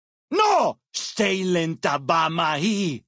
Category:Dead Horses pidgin audio samples Du kannst diese Datei nicht überschreiben.